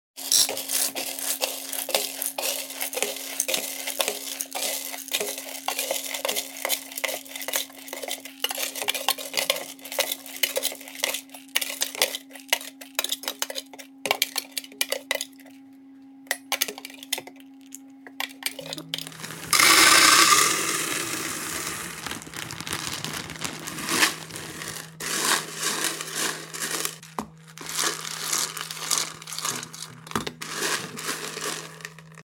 ASMR filling up chocolate raisins sound effects free download